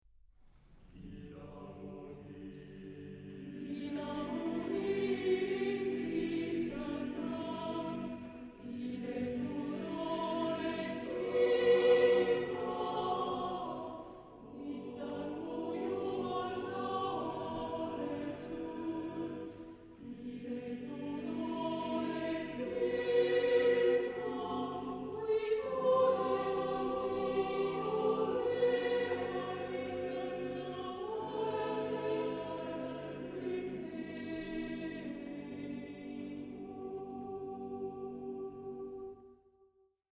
Genre-Stil-Form: Psalm ; geistlich
Chorgattung: SSAATTBB  (8 gemischter Chor Stimmen )
Tonart(en): G-Dur
Aufnahme Bestellnummer: Internationaler Kammerchor Wettbewerb Marktoberdorf